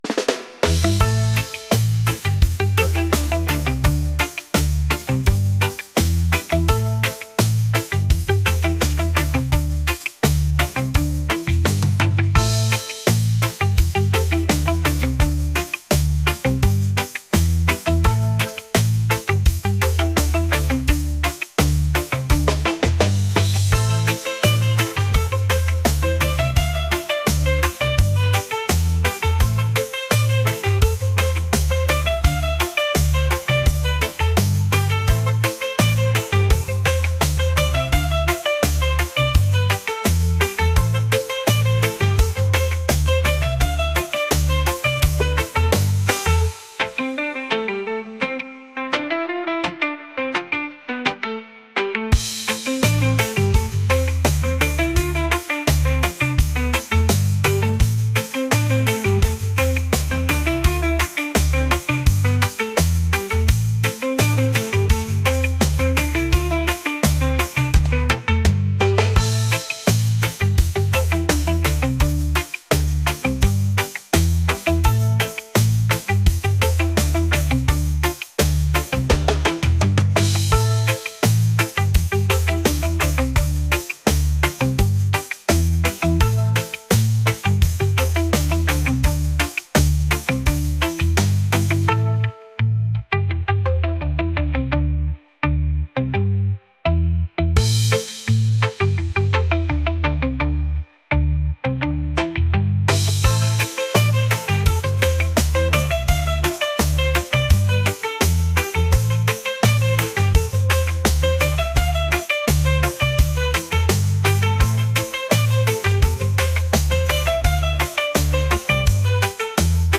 reggae | world | folk